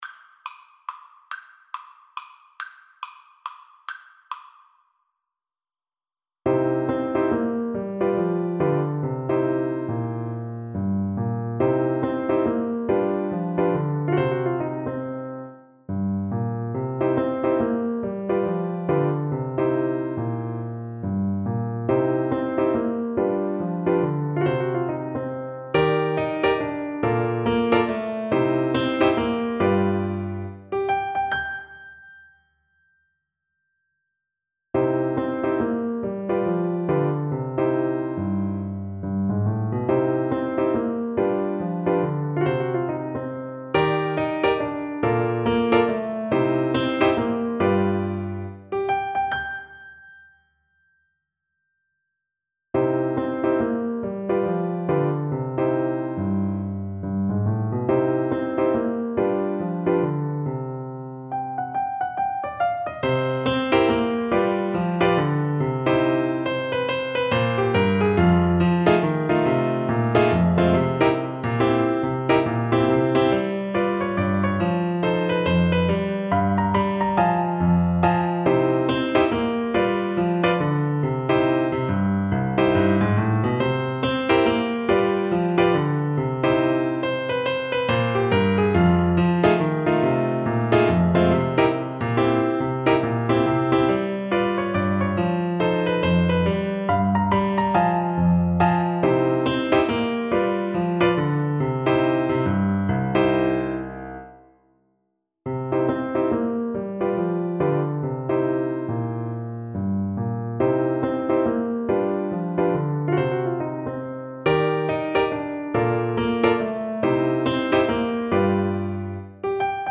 3/4 (View more 3/4 Music)
=140 Fast swing